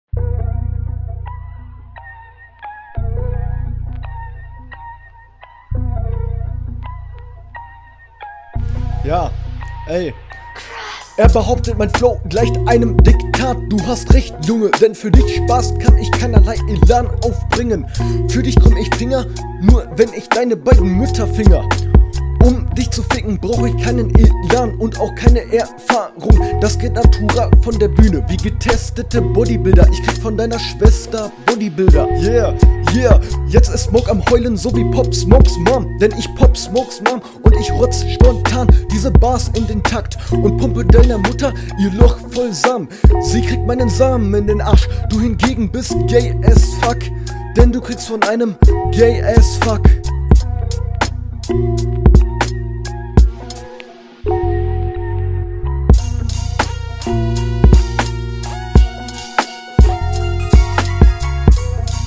Flow ist komplett unrund und nicht anhörbar... brauchst einfach mehr Routine... Bodybuilder Spitt gab es …
Die Betonungen wirken hier nochmal viel aufgesetzter und die Stimme geht im Beat unter. Spits …